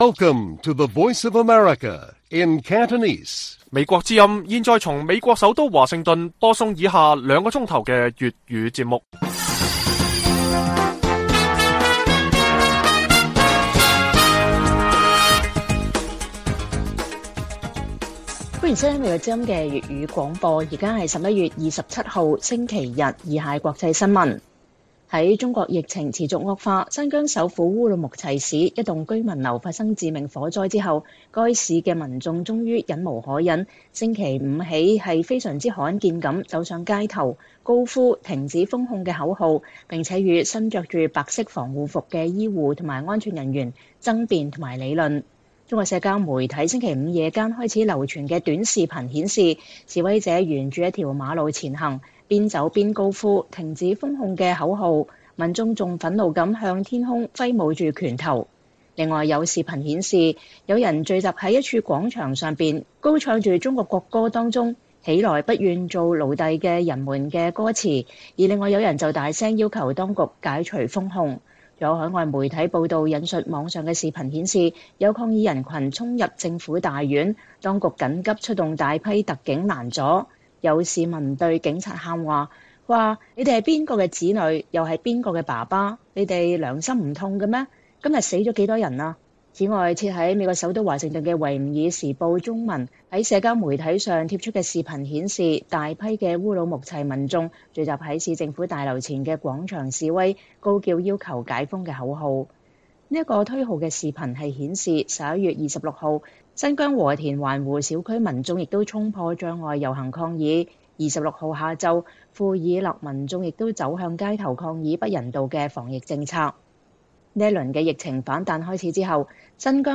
粵語新聞 晚上9-10點：新疆居民樓致命火災後爆發大規模民眾反封控示威